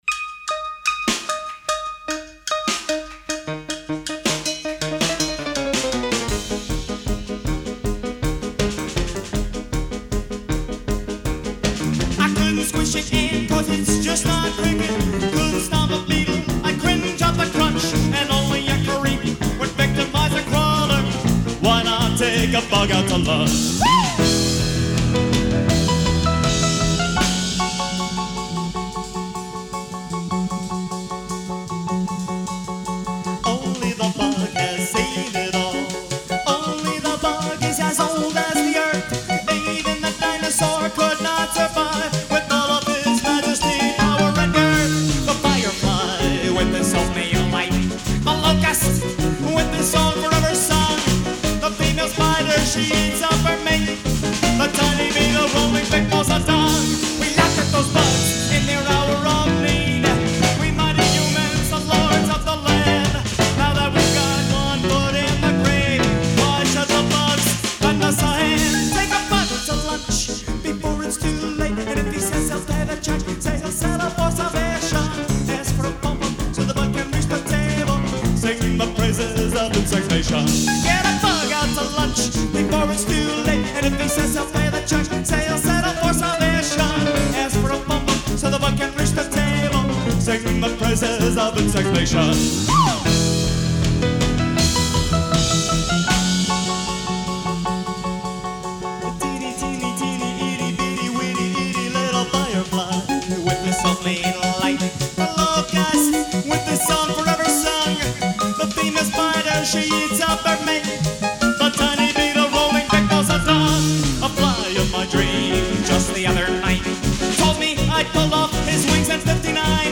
Piano and music
Drums/percussion
Bass